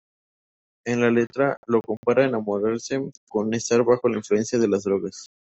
Pronounced as (IPA) /ˈletɾa/